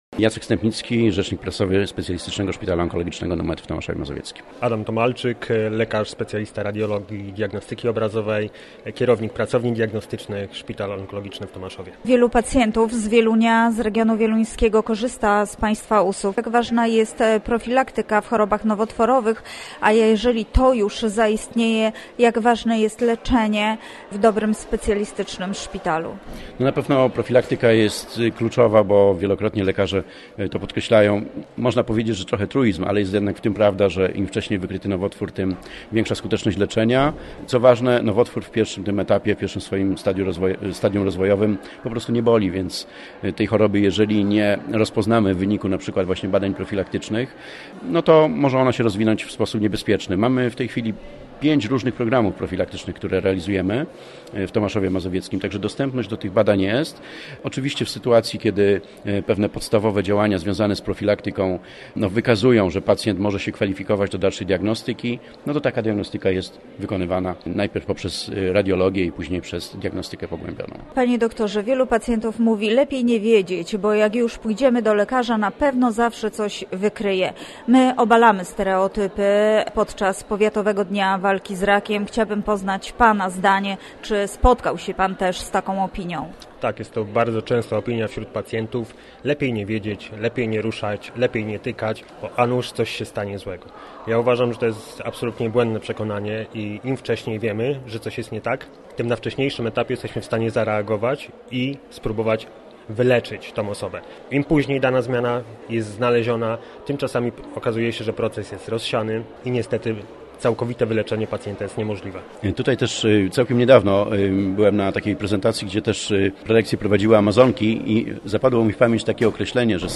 Podczas IX Powiatowego Dnia Walki z Rakiem i Dnia Dawcy Szpiku Kostnego w Wieluniu nasi goście mówili o profilaktyce, diagnostyce i leczeniu raka tarczycy.